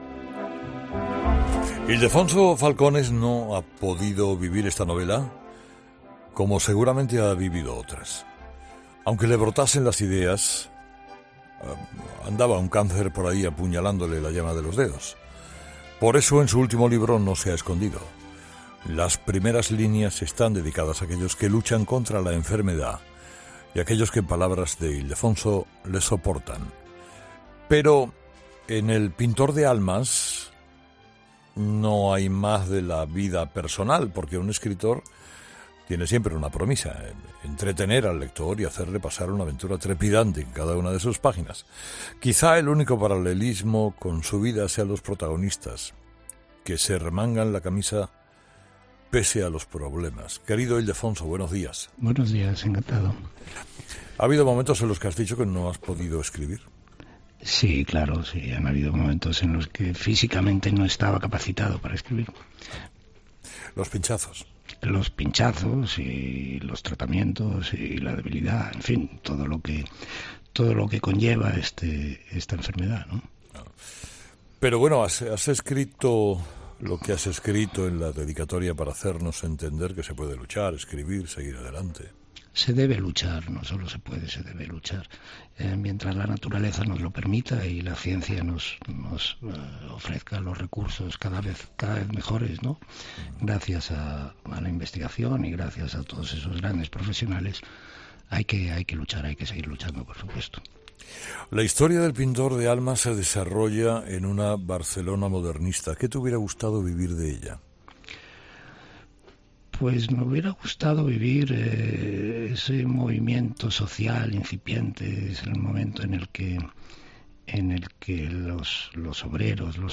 A fin y al cabo, Carlos Herrera le tiene que hacer aún muchas entrevistas.